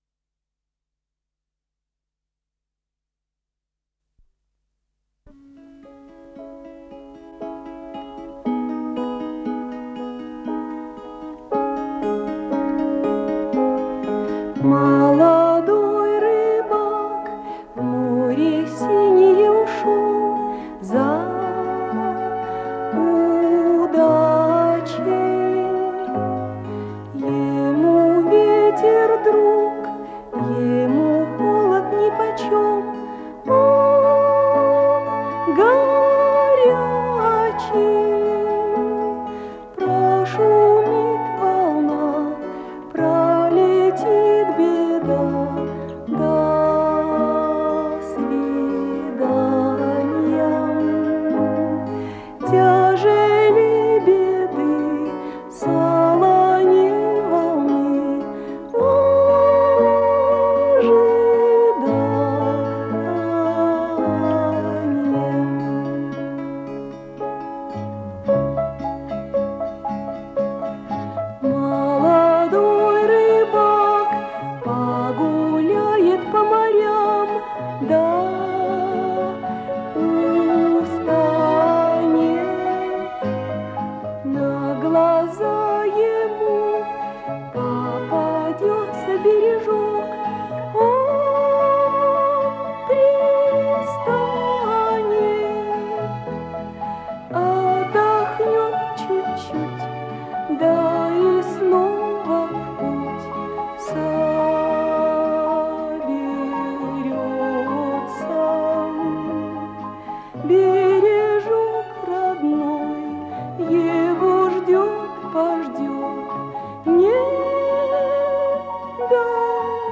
Эфир 29.07.1998 1. Сцена из спектакля